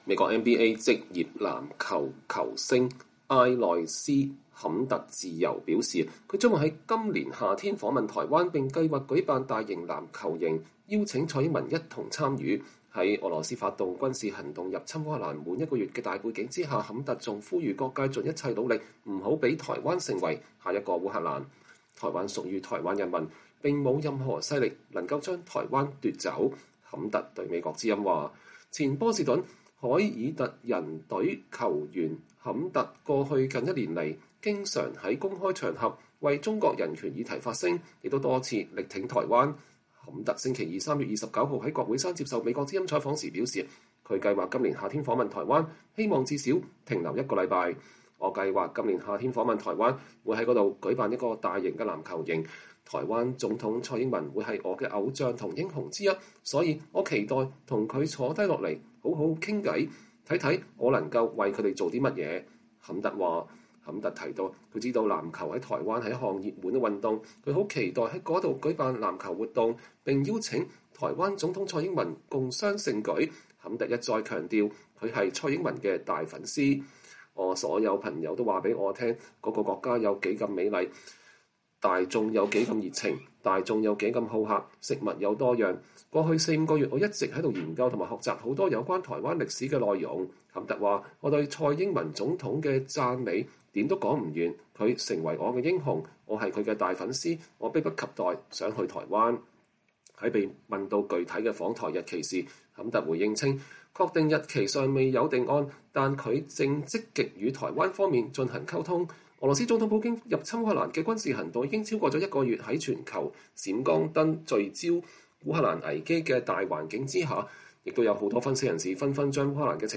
坎特星期二（3月29日）在國會山接受美國之音採訪時表示，他計劃今年夏天訪問台灣，希望至少停留一個星期。